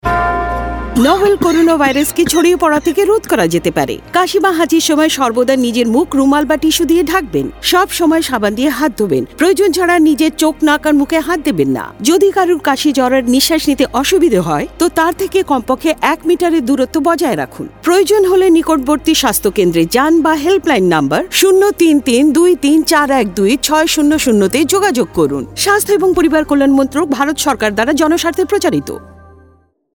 Radio PSA